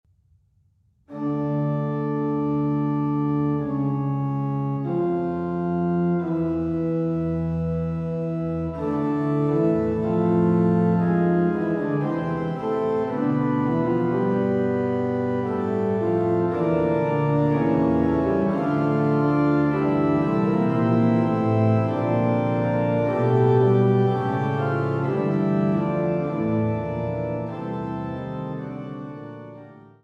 gespielt an der Trost-Orgel der Schlosskirche Altenburg